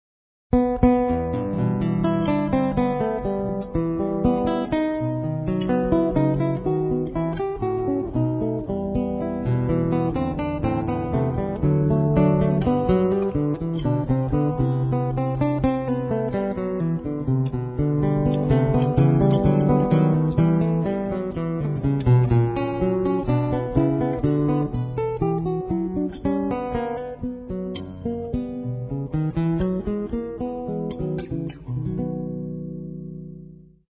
Soprano and alt saxophone
Grand piano
Double bass